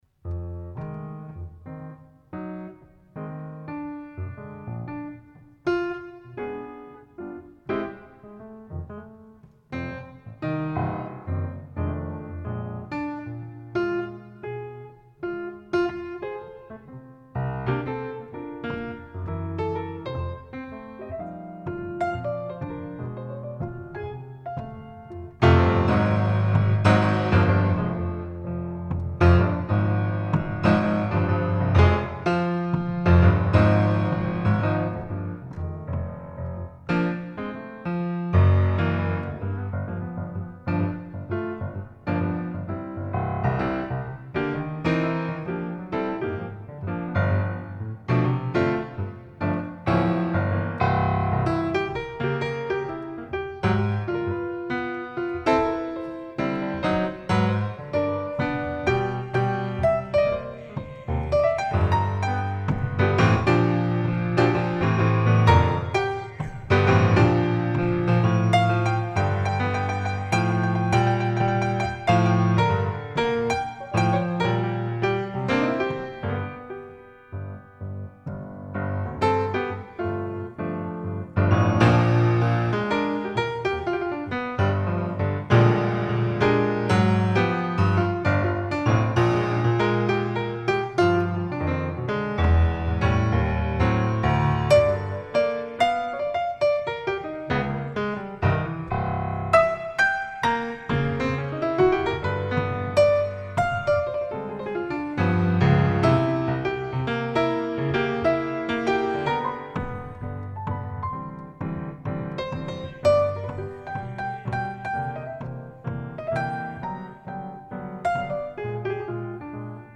Jazz solo piano (live)